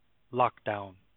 How do you Pronounce Lockdown?
/ˈlɒkdaʊn/
When pronounced correctly it rhymes with Hometown, frown, or clown